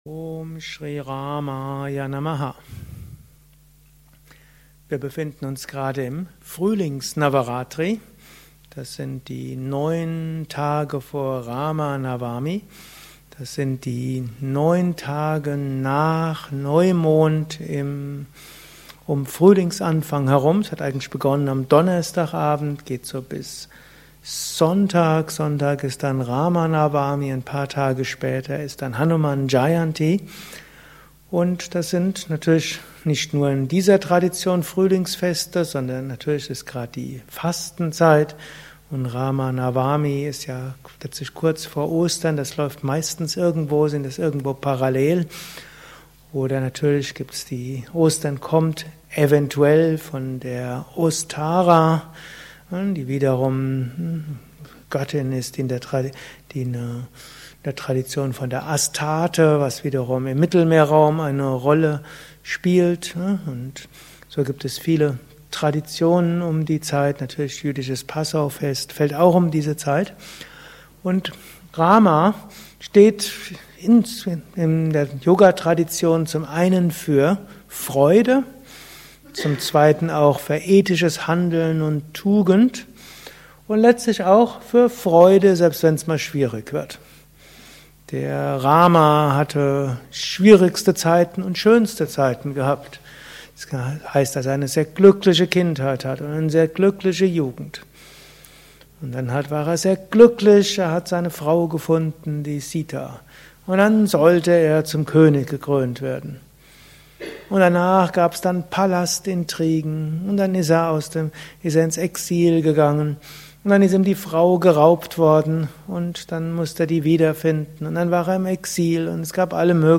Anschluss nach einer Meditation im Haus Yoga Vidya Bad Meinberg.